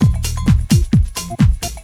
basdrum1.mp3